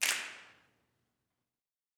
SNAPS 25.wav